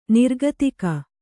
♪ nirgatika